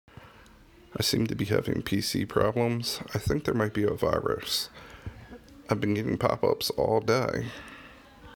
Virus pop-ups